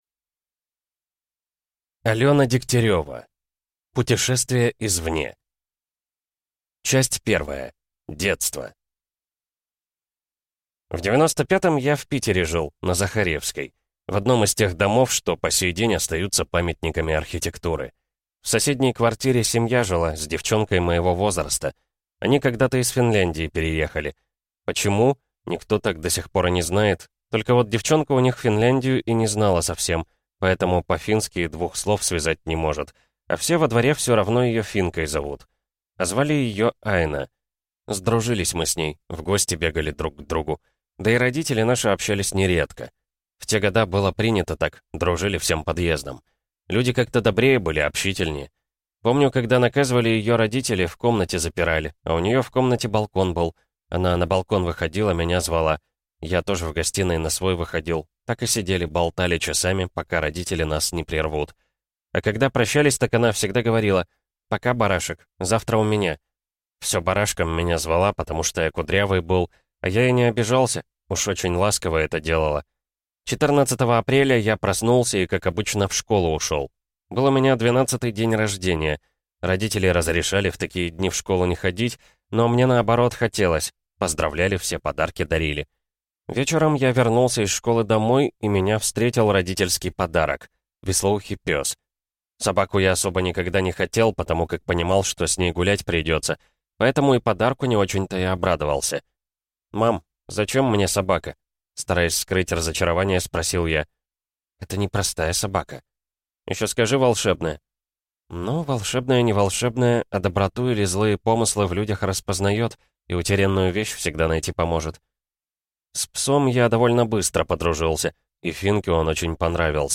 Аудиокнига Путешествие извне | Библиотека аудиокниг